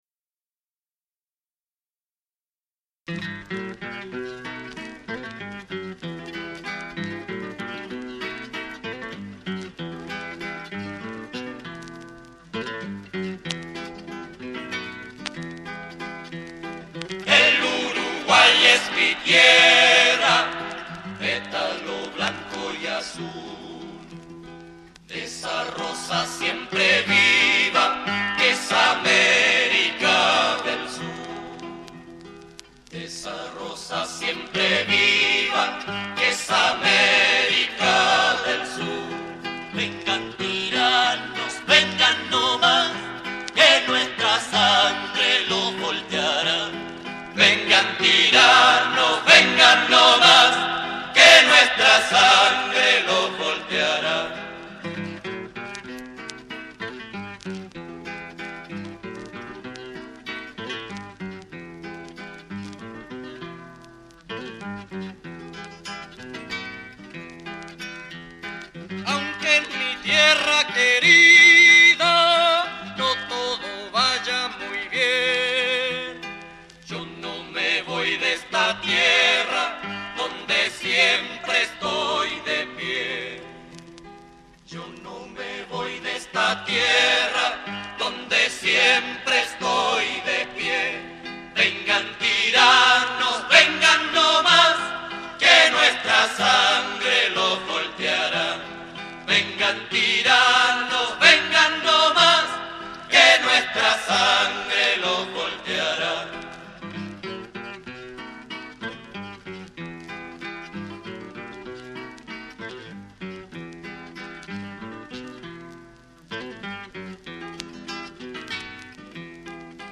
2'45" Vals.